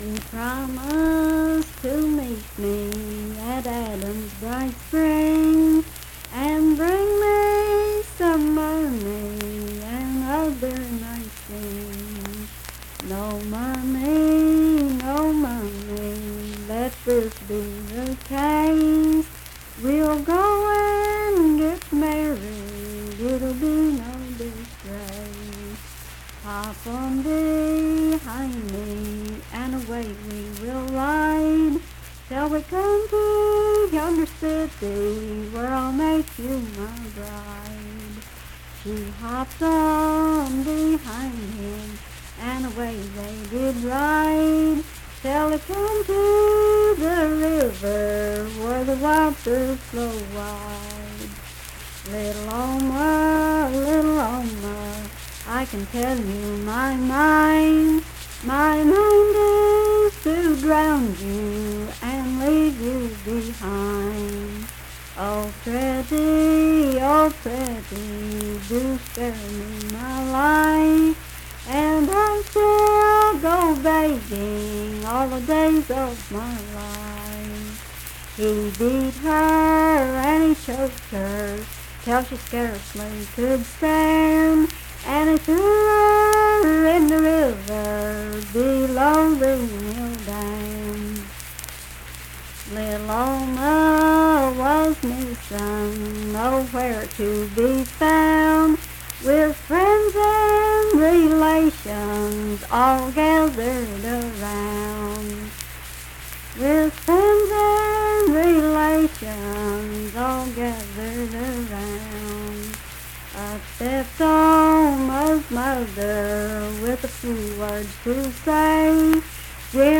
Unaccompanied vocal music
Voice (sung)
Braxton County (W. Va.), Sutton (W. Va.)